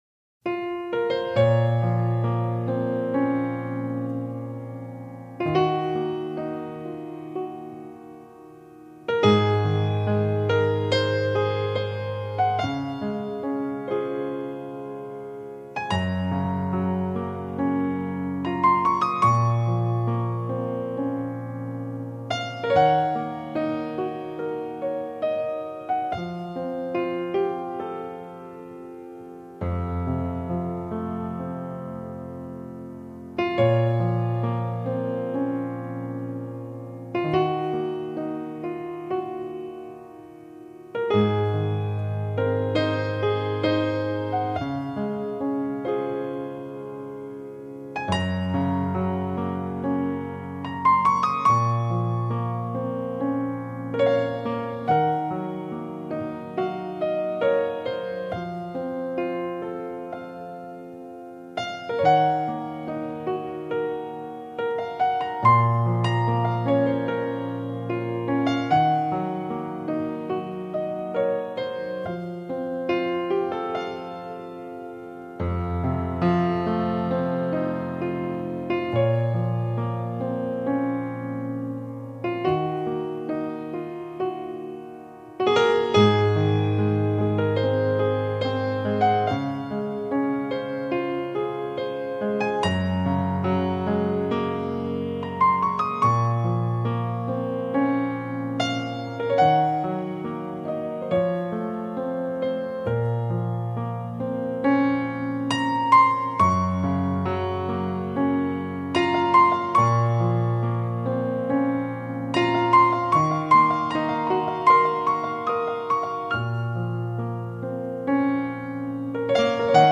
绝 对 唯 美 钢 琴 曲 专 辑 4CD
他深厚的艺术背景，让他的音乐带有感伤的特质，而且有一种特殊的美感。
一首很纯地钢琴曲，在深夜伴我至此时，留恋于其中，被这样地音乐迷恋着不肯离去。
万籁俱寂地夜下，在这舒缓地琴声里。